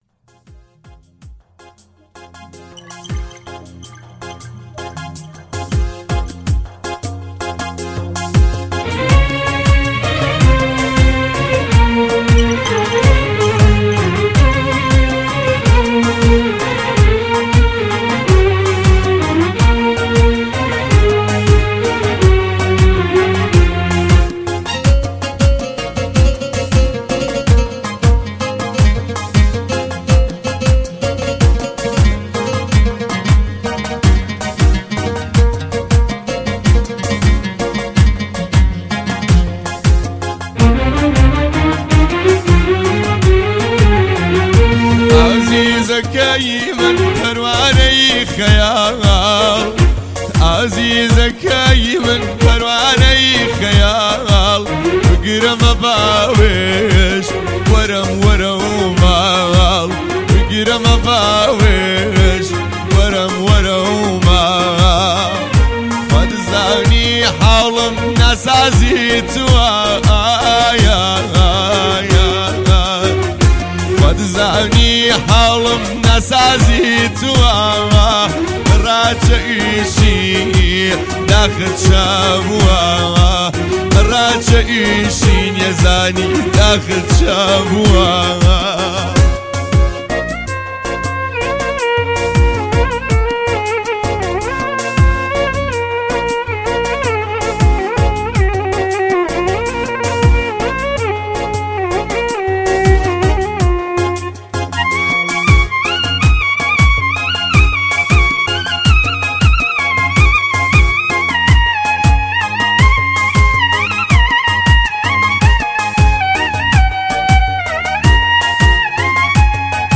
🪕Kurdish music download🪕